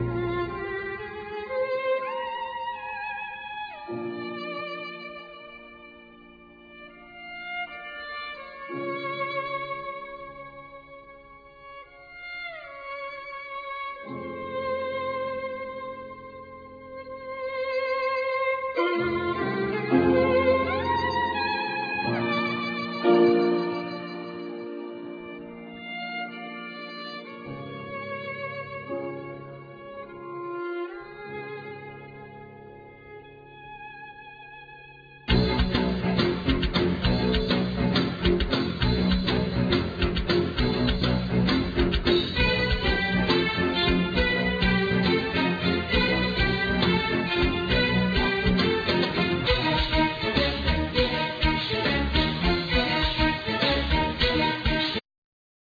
vocals
piano
violin
drums
guitar
bass
keyboards
pipe organ
soprano vocals
cello
double bass
accordion
backing vocals